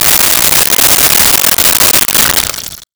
Pour Liquid Into Styrofoam Cup 02
Pour Liquid into Styrofoam Cup 02.wav